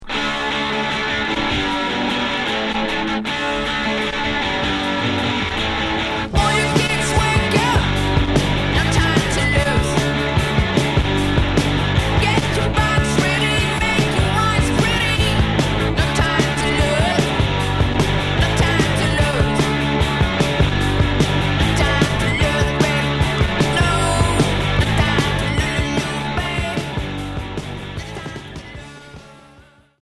Rock Hard